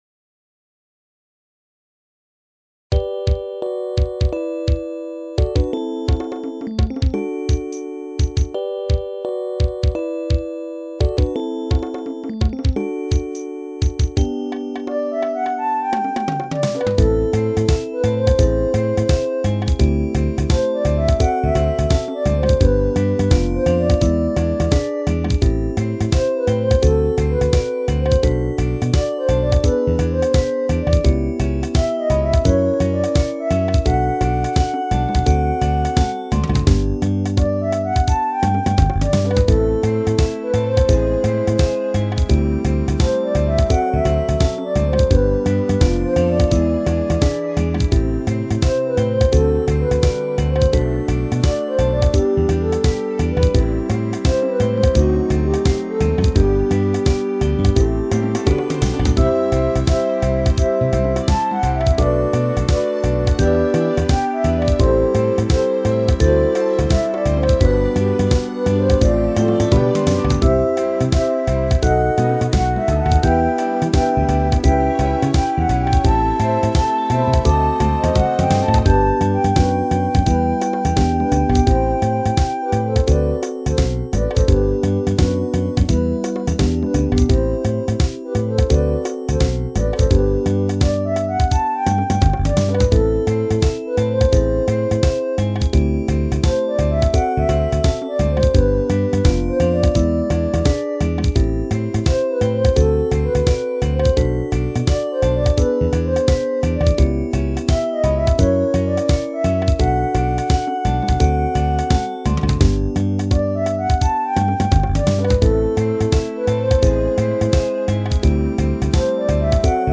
Reggae Ver.
ギターのカッティングこそ入れていないが一応レゲエ調に工夫してみた。
レゲエは4拍目の1:1と2:1のバランスが微妙。